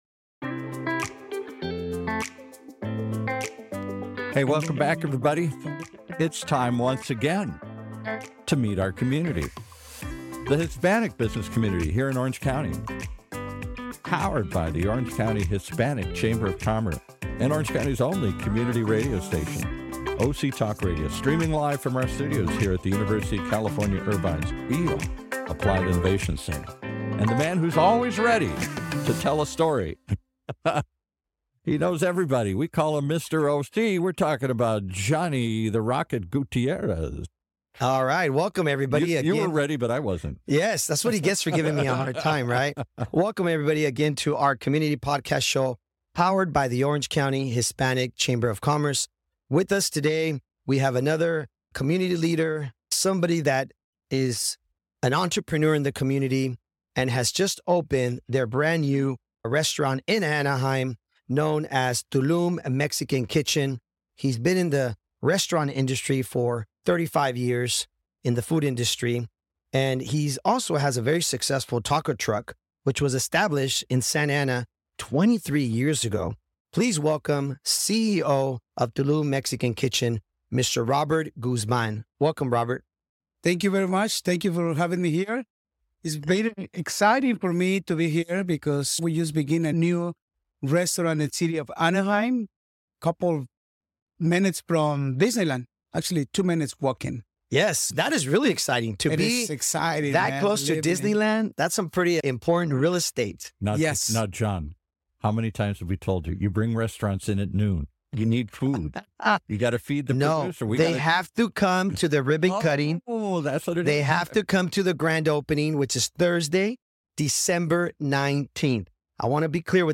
Orange County, CA's only online community radio station, giving businesses a voice on the Internet. Streaming…not screaming talk radio. Streaming live from our studio at the University of California Irvine's BEALL APPLIED INNOVATION CENTER.